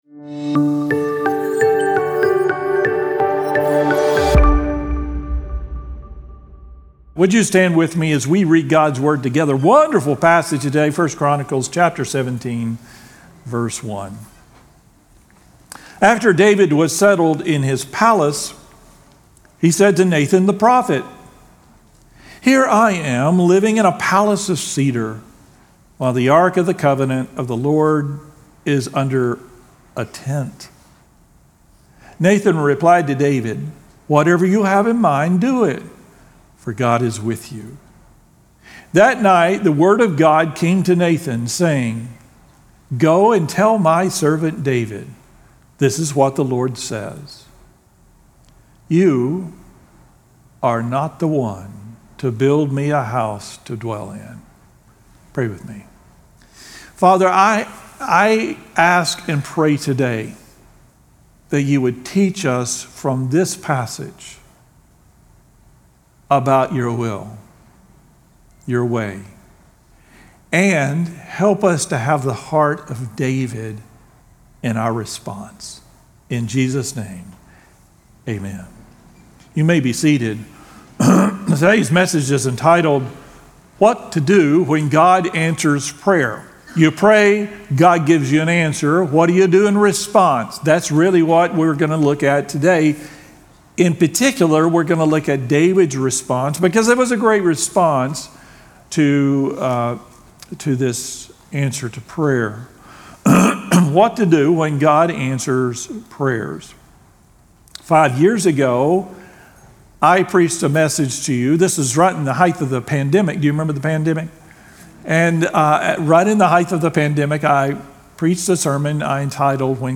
2025-08-24-Sermon-2.mp3